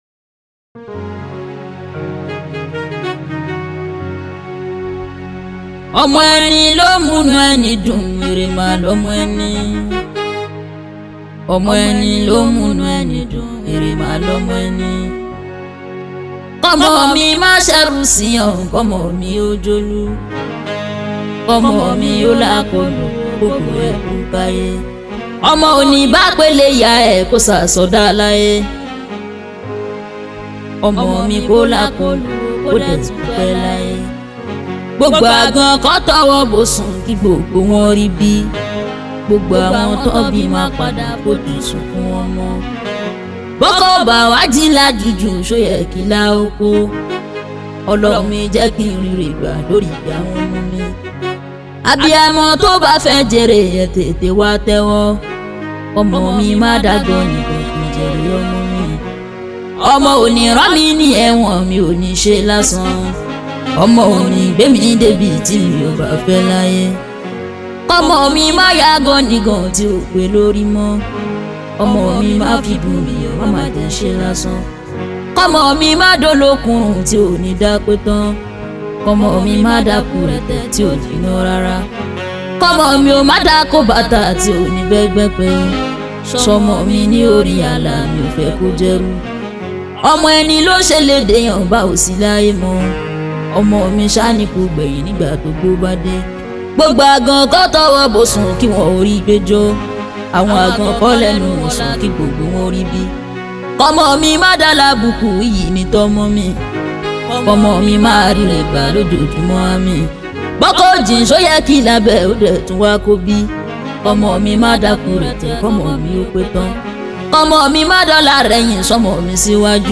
Nigerian Yoruba Fuji track